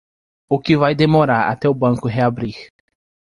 Pronunciado como (IPA)
/ʁe.aˈbɾi(ʁ)/